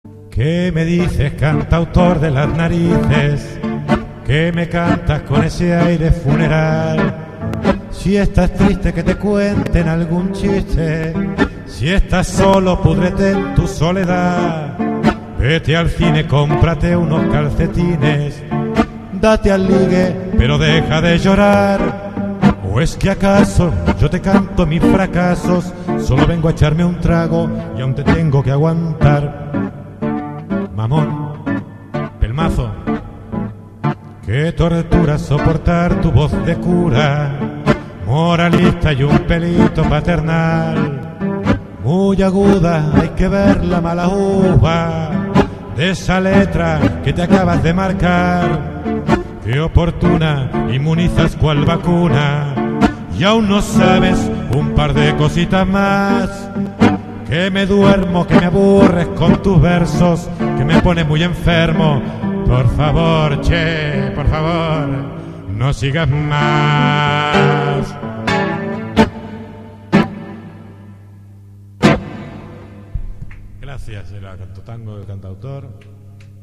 canciones en directo